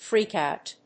アクセントfréak‐òut